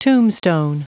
Prononciation du mot tombstone en anglais (fichier audio)
Prononciation du mot : tombstone